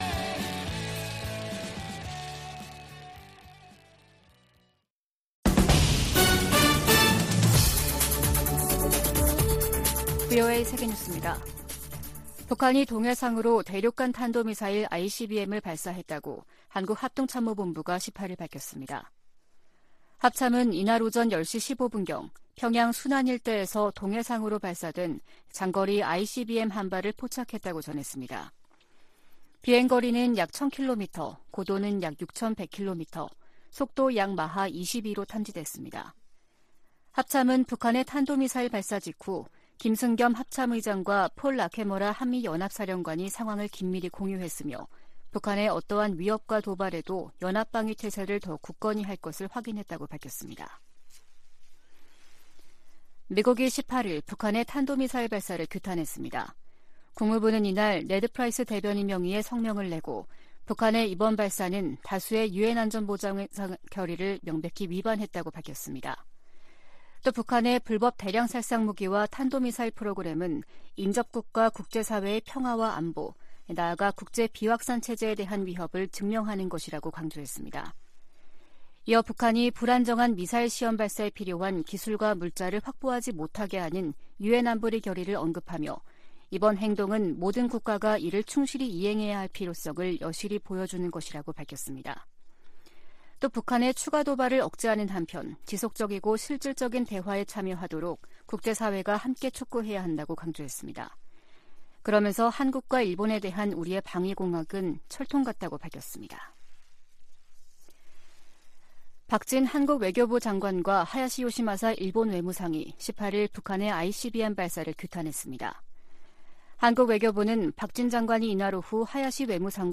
VOA 한국어 아침 뉴스 프로그램 '워싱턴 뉴스 광장' 2022년 11월 19일 방송입니다. 북한이 오늘, 18일, 신형 대륙간탄도미사일, ICBM인 ‘화성-17형’을 시험발사해 정상비행에 성공한 것으로 알려졌습니다. 한국 합동참모본부는 이에 대응해 F-35A 스텔스 전투기를 동원해 북한 이동식발사대(TEL) 모의표적을 타격하는 훈련을 실시했습니다.